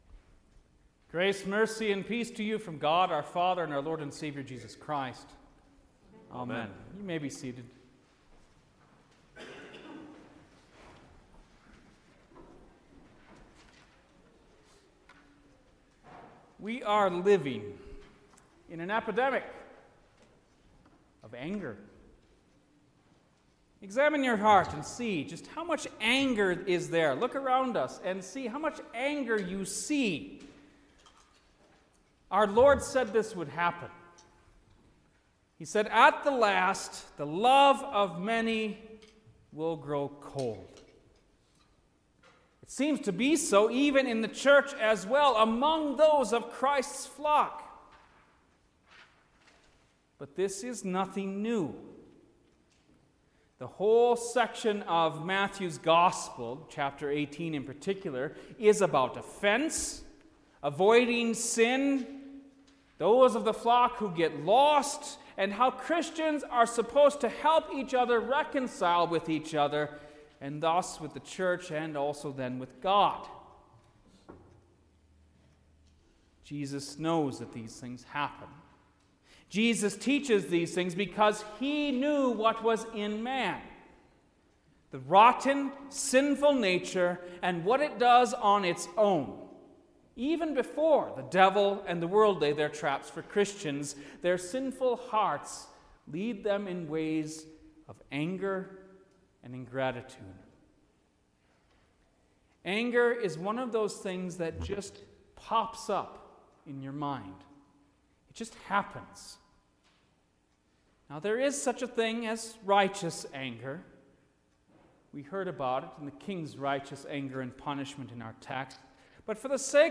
November-8-Twenty-Second-Sunday-After-Trinity_Sermon.mp3